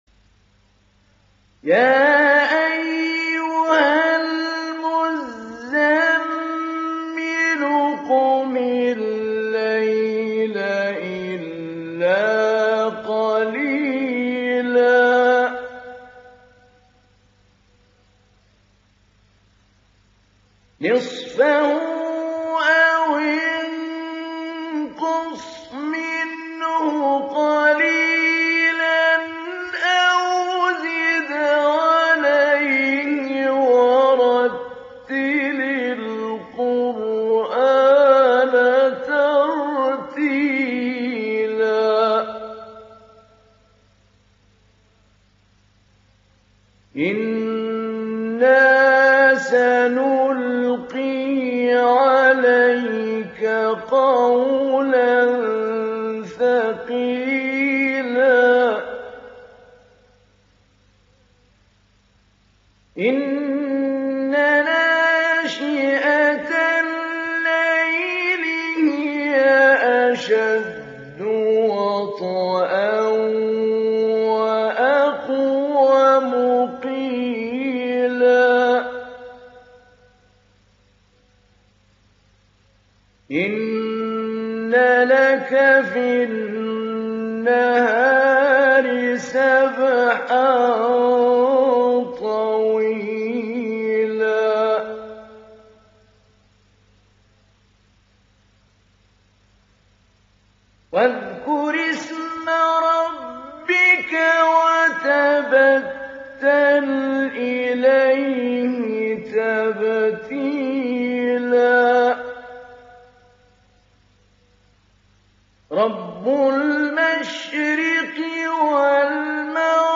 İndir Müzemmil Suresi Mahmoud Ali Albanna Mujawwad